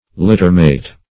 Search Result for " littermate" : The Collaborative International Dictionary of English v.0.48: littermate \lit"ter*mate`\ (l[i^]t"t[~e]r*m[=a]t`), n. One of two or more animals born into the same litter.
littermate.mp3